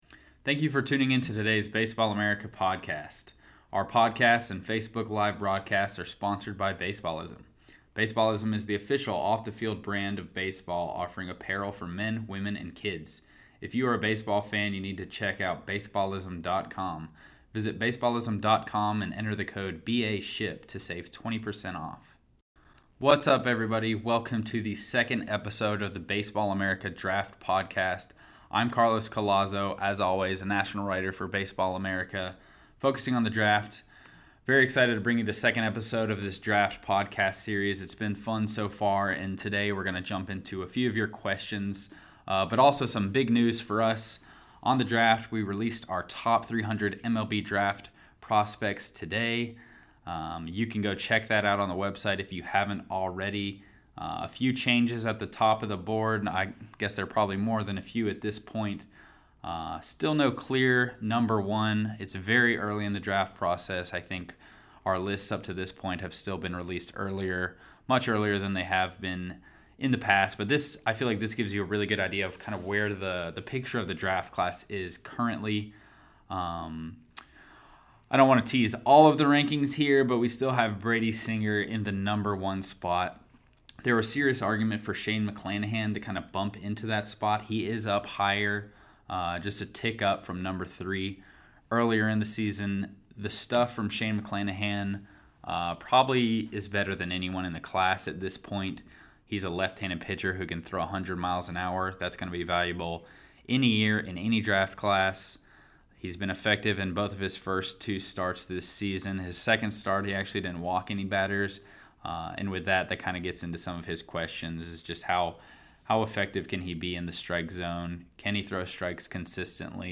There are also interviews